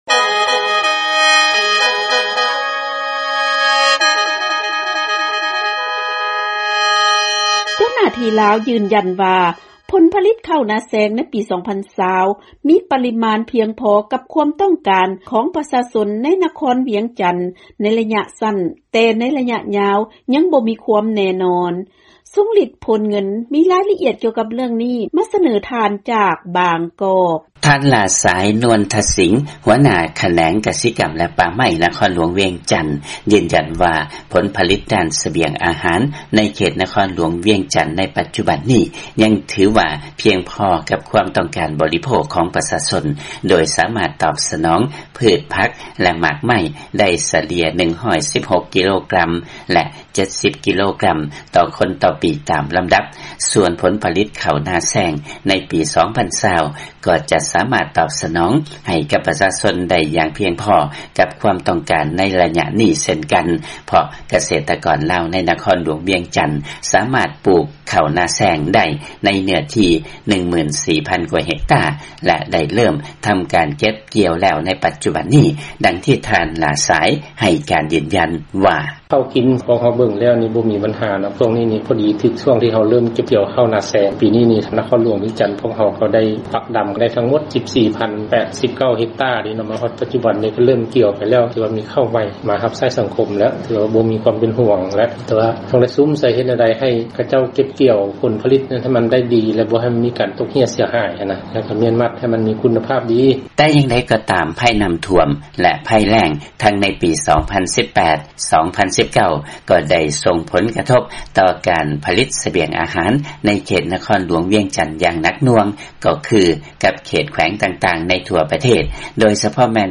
ເຊີນຟັງລາຍງານ ຜົນຜະລິດເຂົ້ານາແຊງ ໃນປີ 2020 ມີປະລິມານພຽງພໍ ກັບຄວາມຕ້ອງການຂອງ ປຊຊ ໃນນະຄອນວຽງຈັນ ໃນໄລຍະສັ້ນ